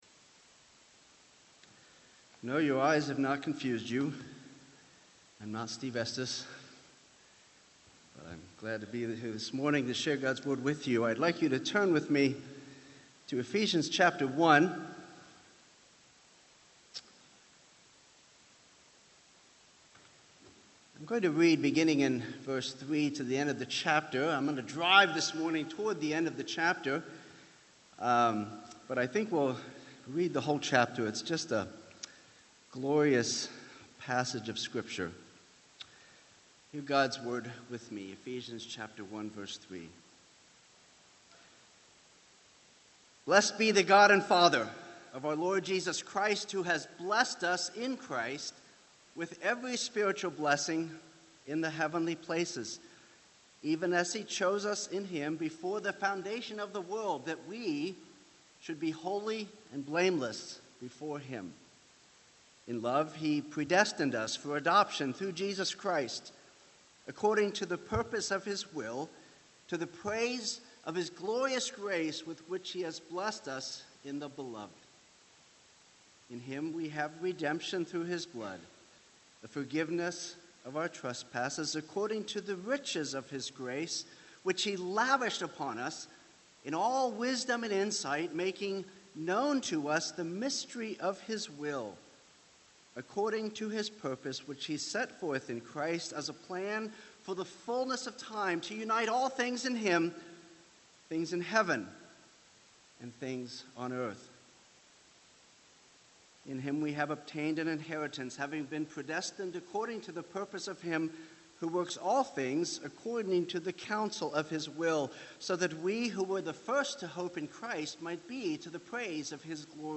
Ephesians — Audio Sermons — Brick Lane Community Church